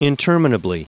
Prononciation du mot interminably en anglais (fichier audio)
Prononciation du mot : interminably